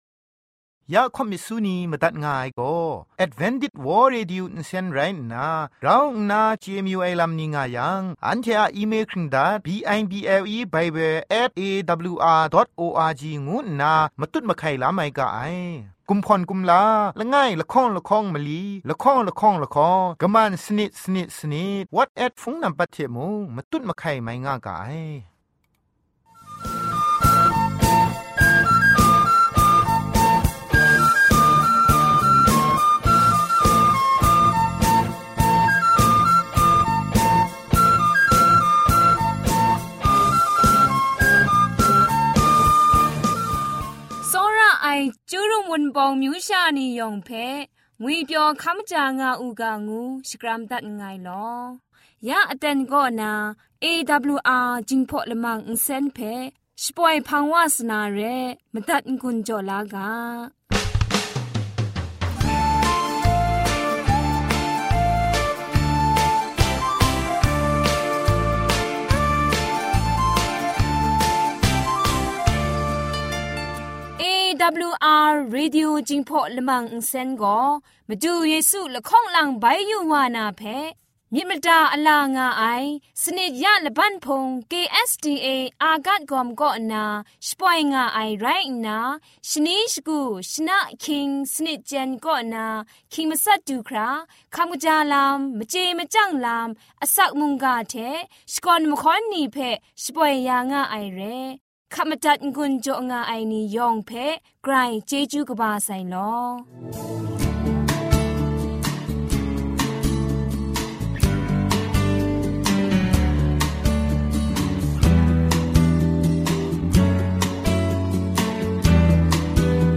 gospels song,health talk,sermon.